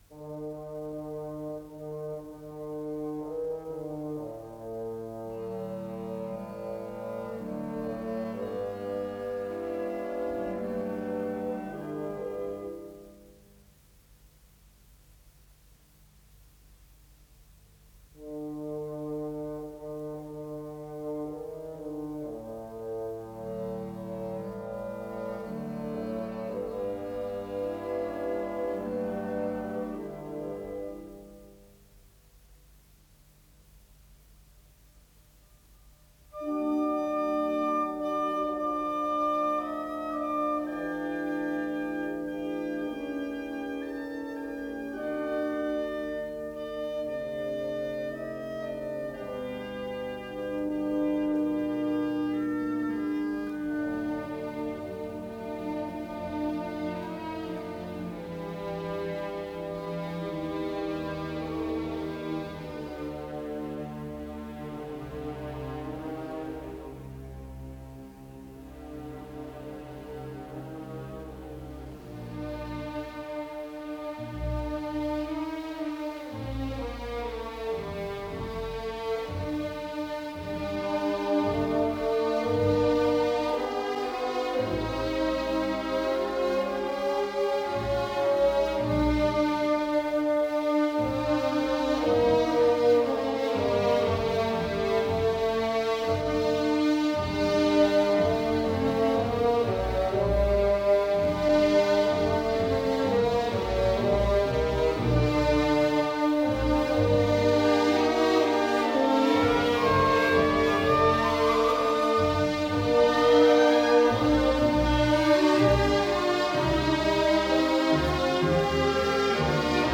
Исполнитель: Государственный симфонический оркестр СССР
Название передачи Симфония №1 "Зимние грёзы" Подзаголовок Соч. 13. Соль минор.